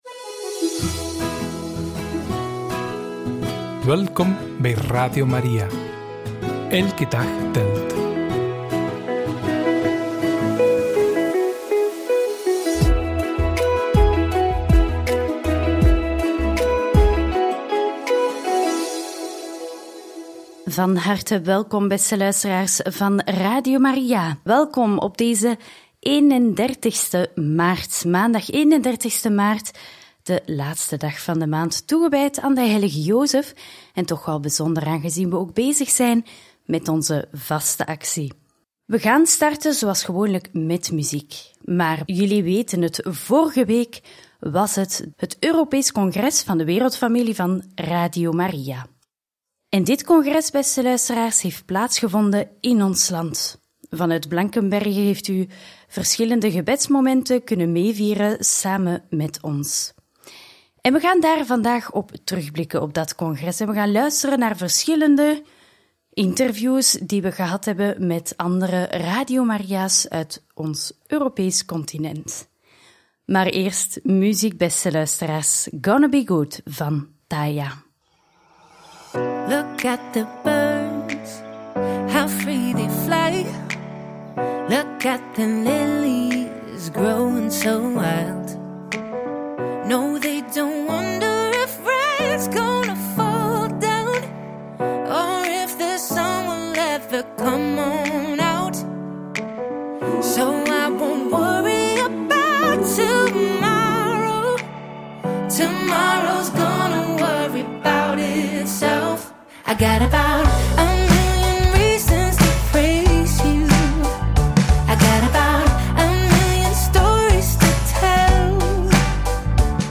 Europees congres van Radio Maria: in gesprek met Radio Maria Slovakije en de delegatie van Radio Maria Kroatië!